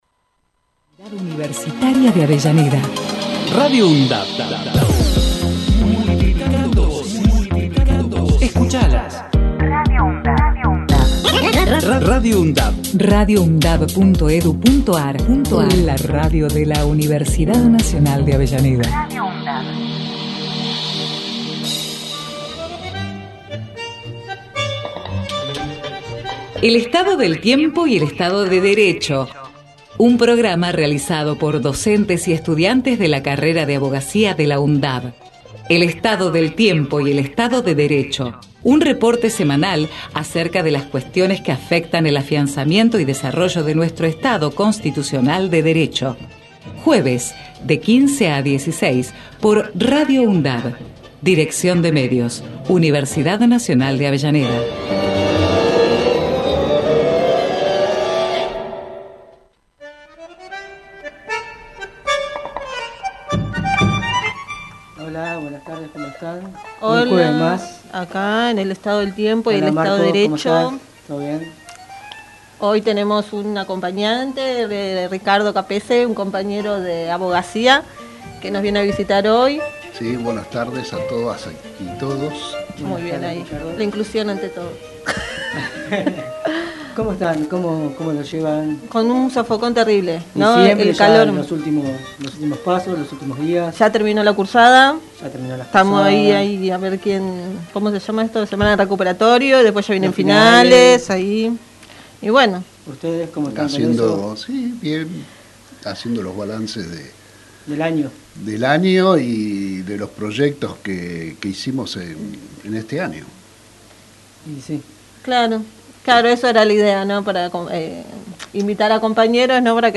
El Estado del Tiempo y el Estado de Derecho Texto de la nota: El Estado del Tiempo y el Estado de Derecho es un programa realizado por estudiantes y docentes de la carrera de Abogacía de la Universidad Nacional de Avellaneda, fue emitido por Radio UNDAV desde el año 2016 todos los jueves de 15 a 16hs.